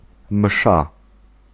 Ääntäminen
Synonyymit liturgie Ääntäminen France: IPA: [mɛs] Haettu sana löytyi näillä lähdekielillä: ranska Käännös Konteksti Ääninäyte Substantiivit 1. msza {f} uskonto Suku: f .